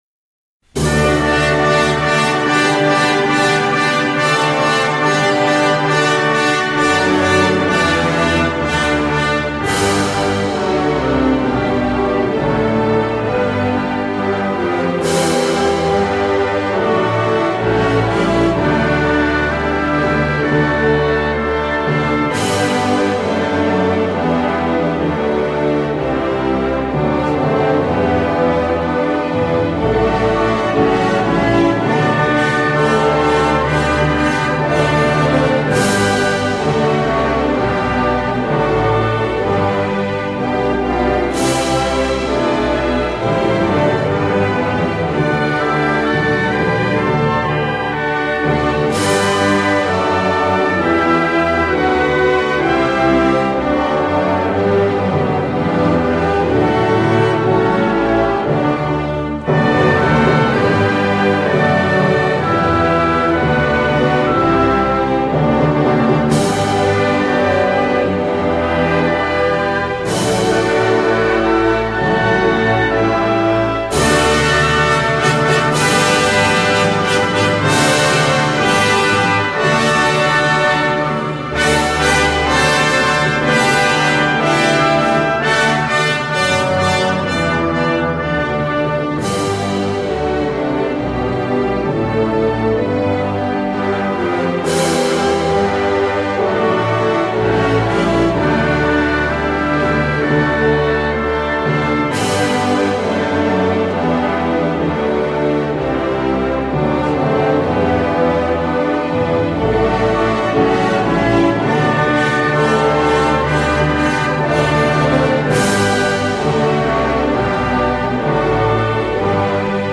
торжественное музыкальное произведение
инструментальная версия